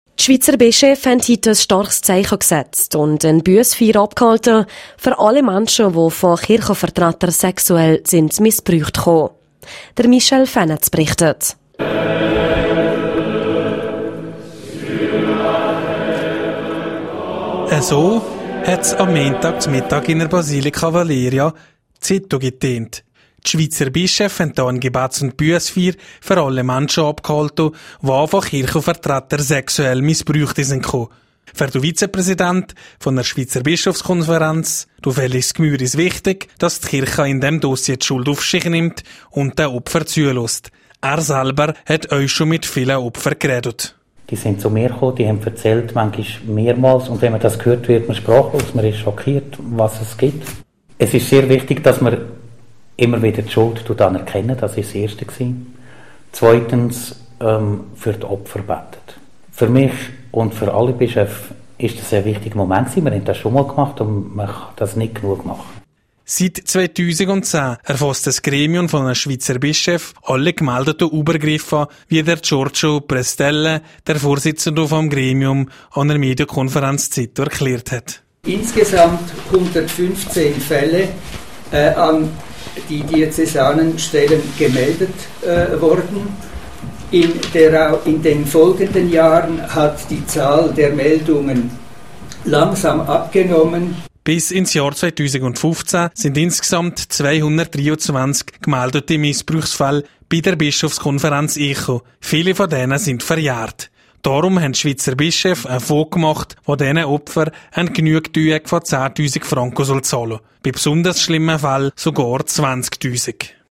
Bussfeier der Schweizer Bischöfe in Sitten.